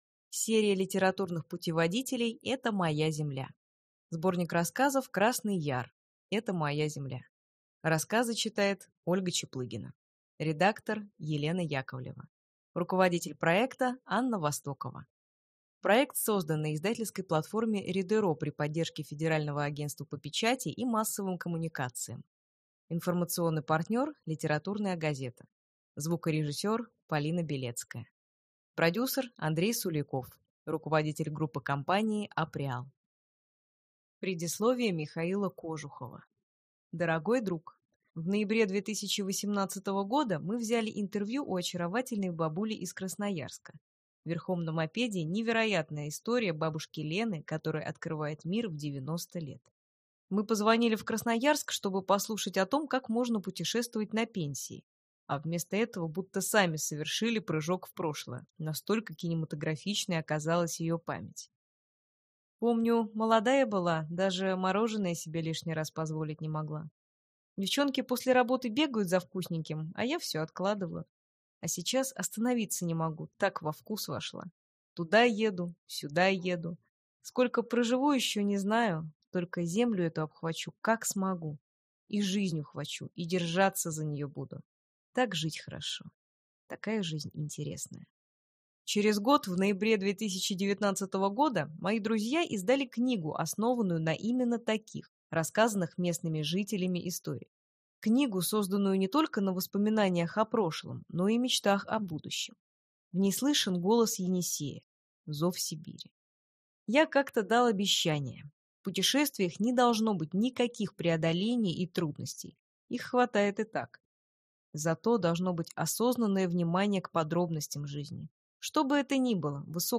Аудиокнига Красный Яр. Это моя земля. Литературный путеводитель | Библиотека аудиокниг